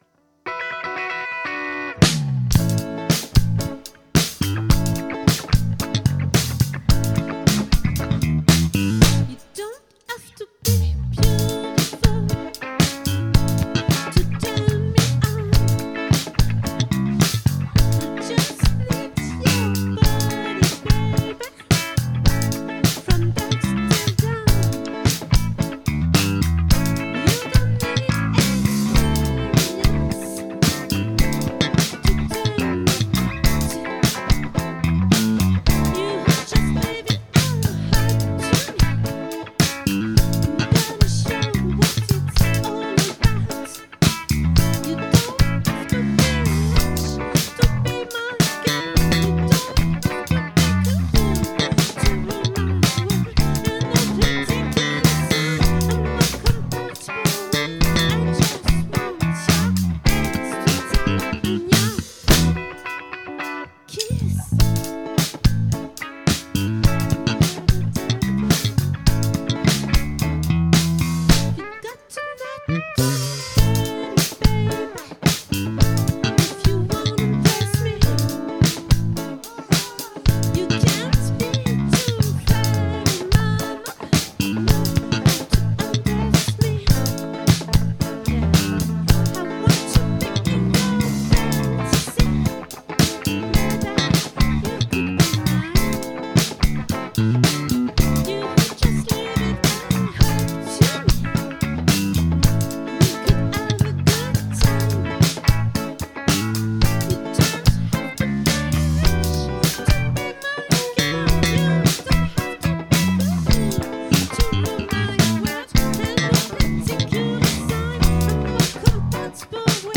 🏠 Accueil Repetitions Records_2025_12_22